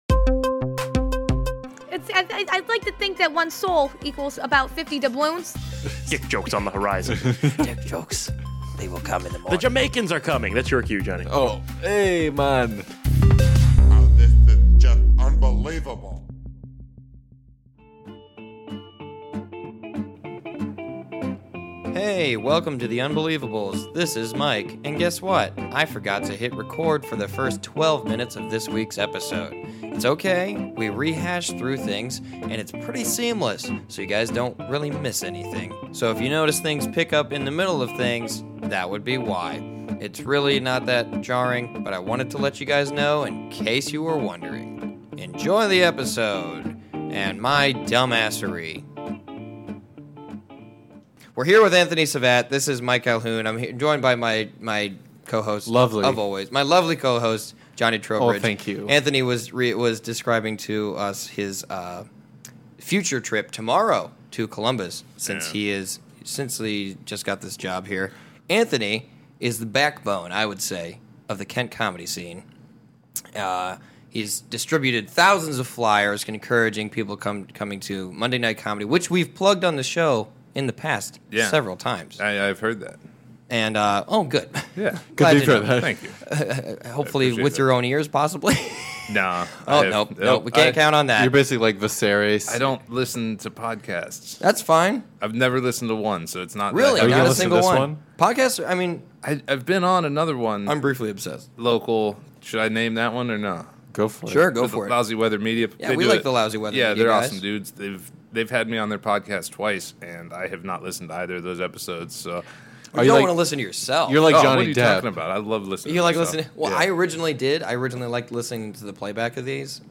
we've been told his changing voice is a result of all the nyquil he was consuming